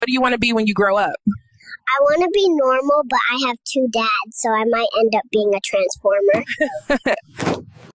💀😂 AI skits are getting out of control — babies shouldn’t have dialogue this deep. The funniest part is the delivery: no hesitation, just straight talk like she’s on a TED stage.